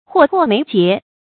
禍迫眉睫 注音： ㄏㄨㄛˋ ㄆㄛˋ ㄇㄟˊ ㄐㄧㄝ ˊ 讀音讀法： 意思解釋： 猶言禍在旦夕。